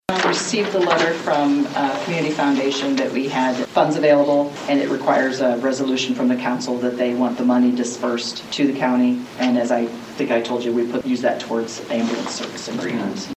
(LAGRANGE) – The LaGrange County Council approved a resolution to put funds towards the ambulance service during their meeting Monday.
County Auditor Kathy Hopper shares with the Council that a letter had been sent saying money was available for the ambulance service.